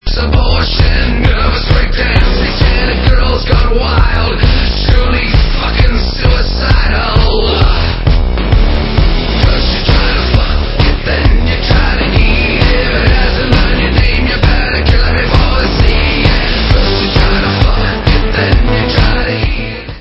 velmi silnými kytarovými motivy
sledovat novinky v kategorii Rock